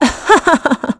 Valance-Vox-Laugh.wav